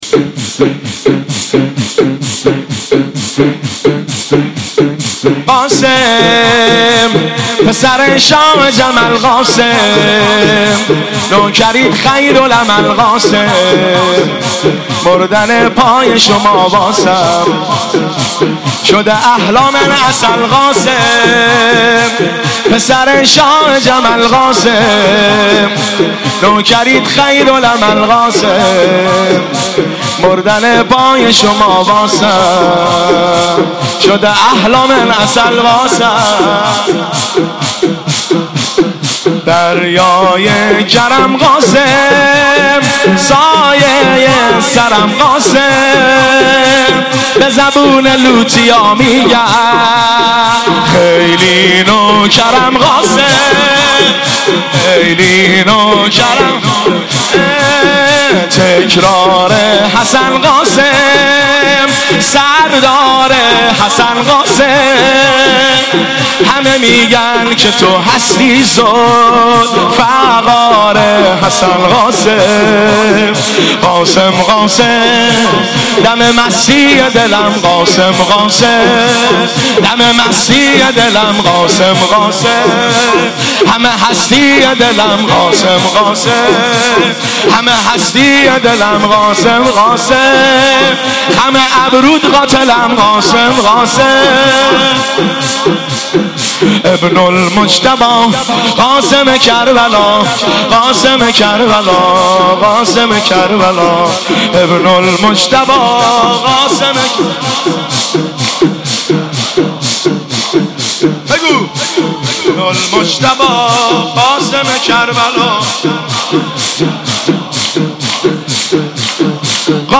مداحی شب پنجم محرم